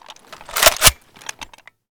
groza_unjam.ogg